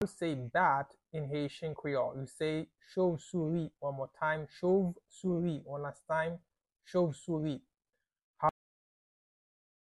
Pronunciation:
Bat-in-Haitian-Creole-Chovsouri-pronunciation-by-a-Haitian-teacher.mp3